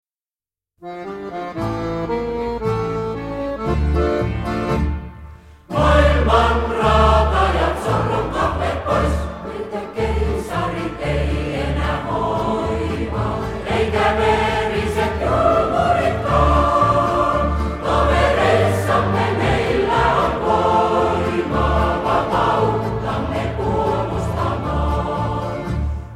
Vanha venäl. marssi